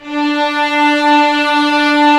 Index of /90_sSampleCDs/Roland L-CD702/VOL-1/STR_Vlns 6 mf-f/STR_Vls6 f wh%